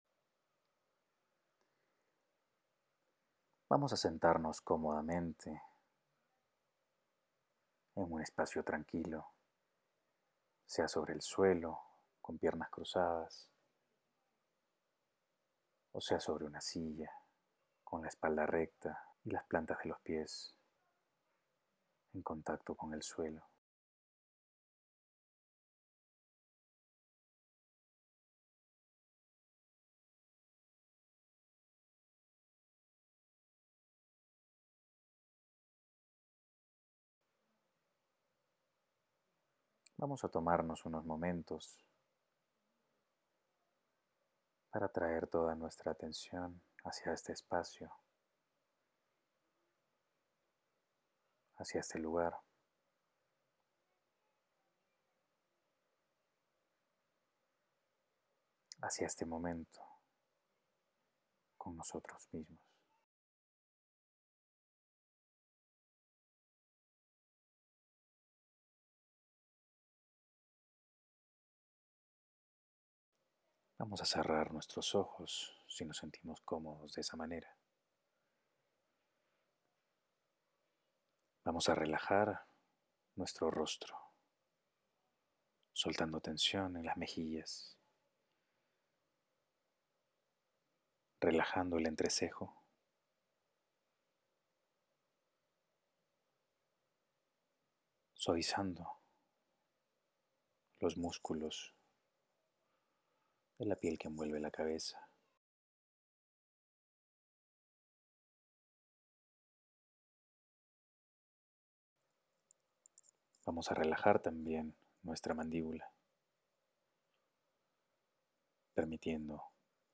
Meditación